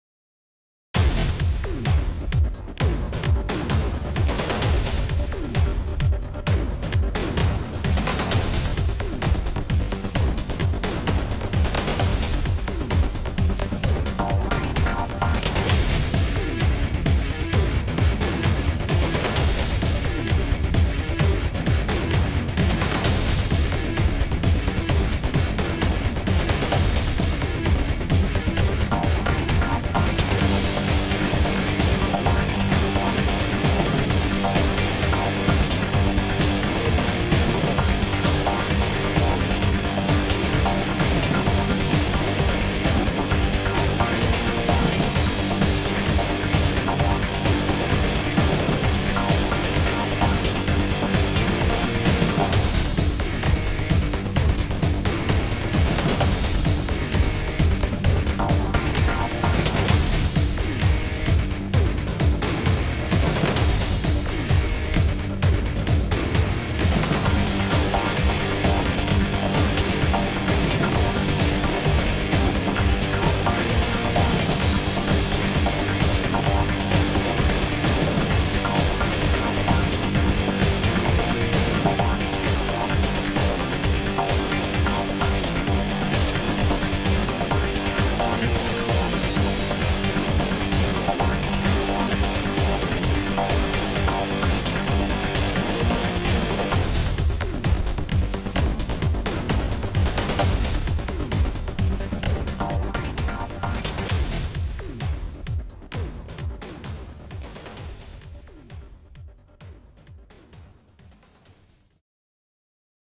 is more like a set of loops